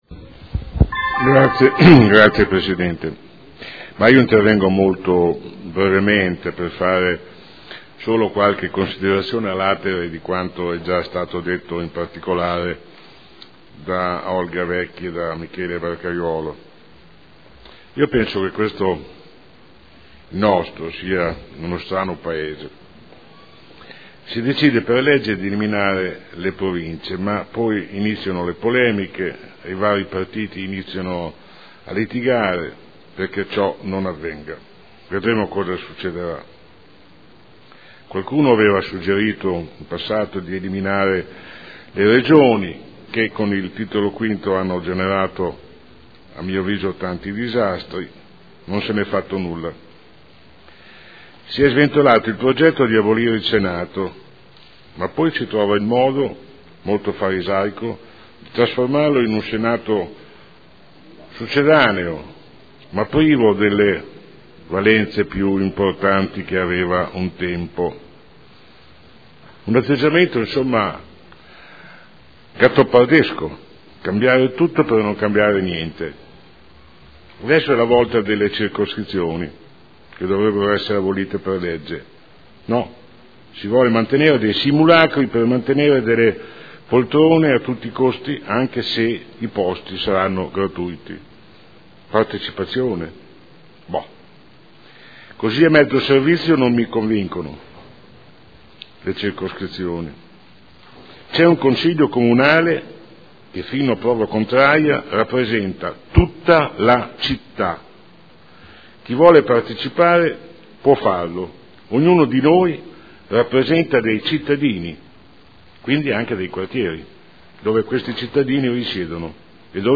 Seduta del 3 aprile. Proposta di deliberazione: Regolamento di prima attuazione della partecipazione territoriale – Approvazione. Dibattito